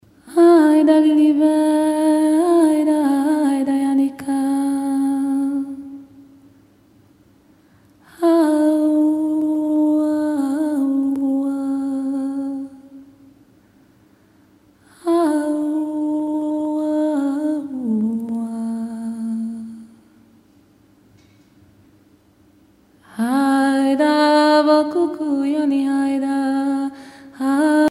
Informateur(s) Palya Beata
Concert a Brugges Festival, Belgique
Pièce musicale inédite